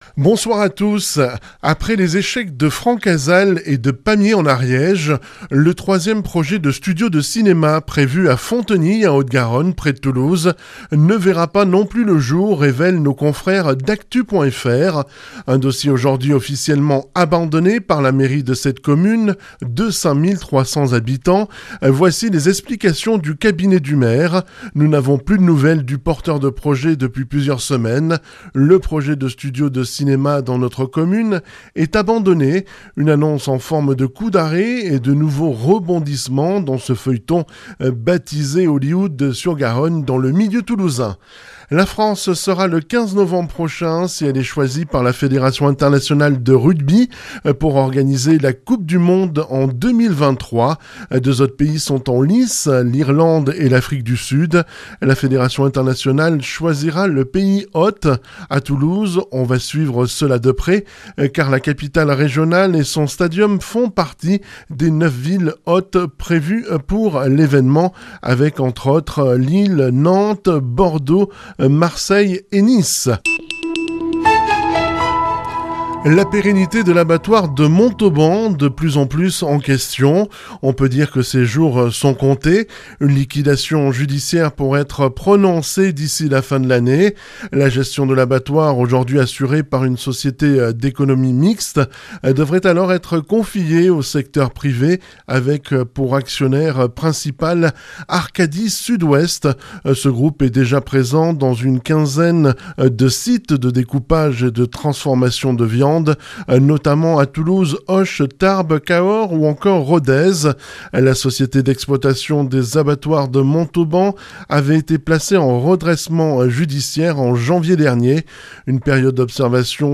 JOURNAL REGION
Journal local